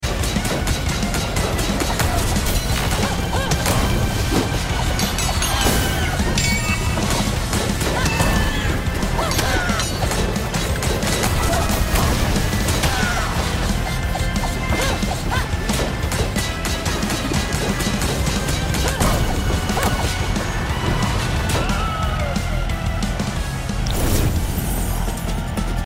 motorcycle phase (fase de moto)